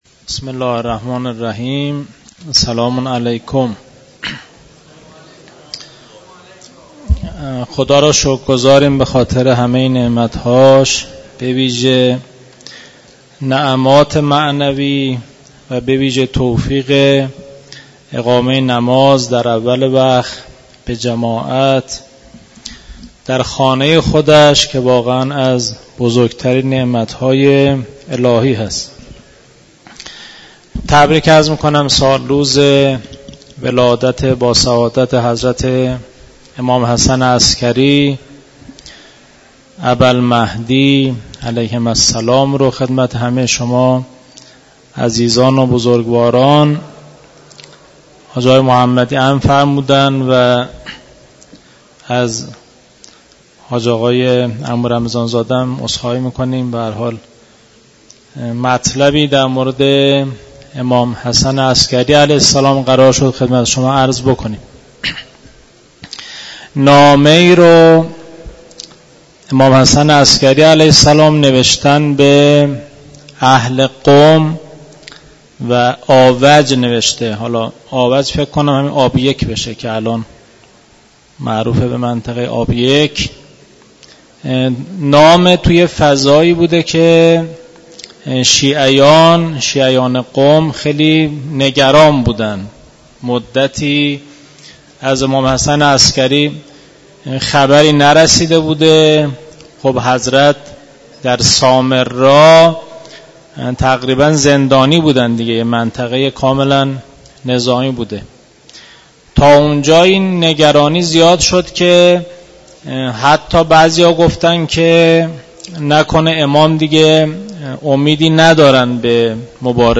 سخنرانی
به مناسبت میلاد باسعادت امام حسن عسگری ع در مسجد دانشگاه کاشان